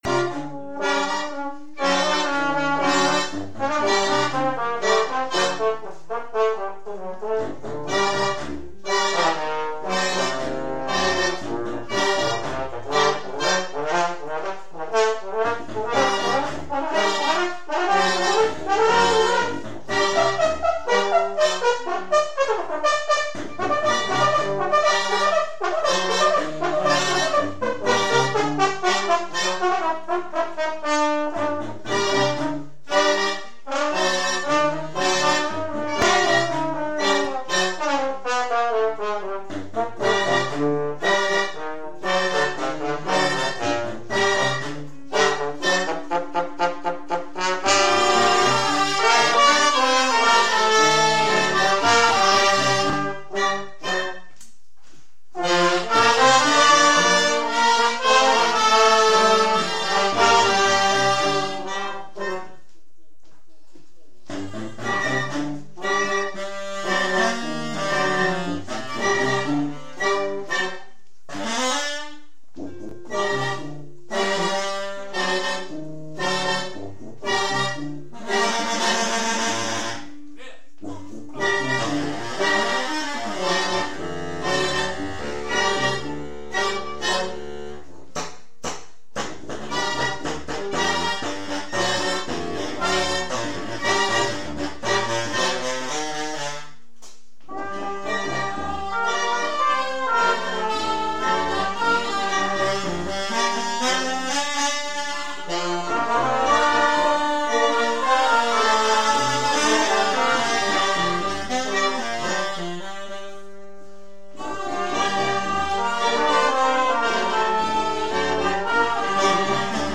Enregistrements Audio lors du 1er stage 2014
Atelier improvisations sur LaSiDoRéMi  :
impros.mp3